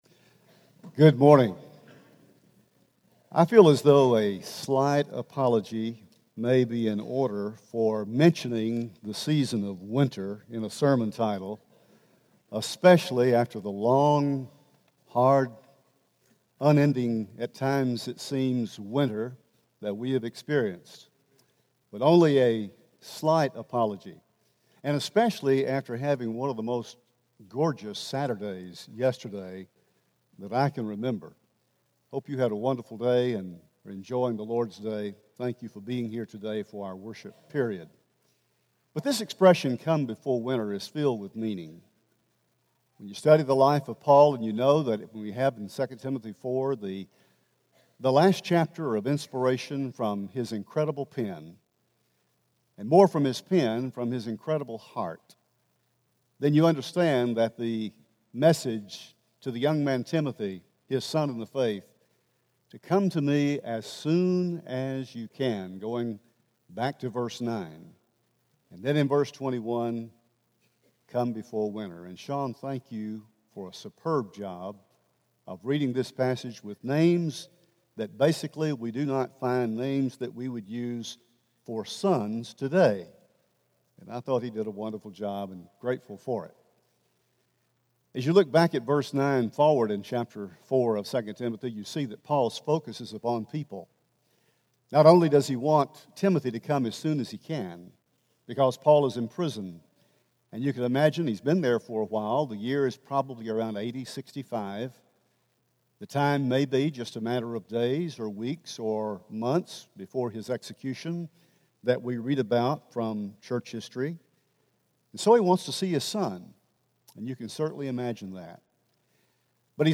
Come Before Winter – Henderson, TN Church of Christ